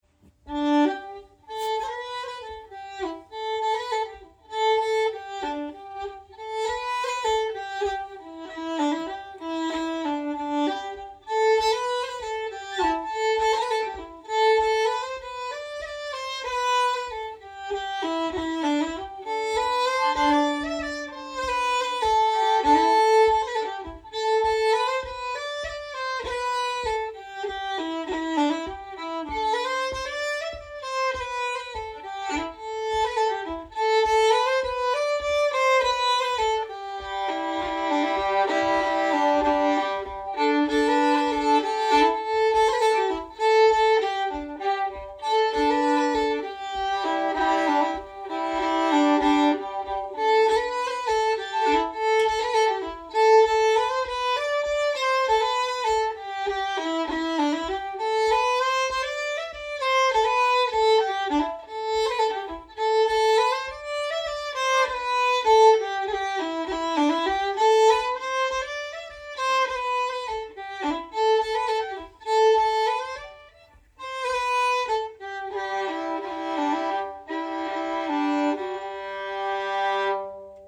This is a bit of a folk-processed version.
Composer Traditional Type Reel Key G Recordings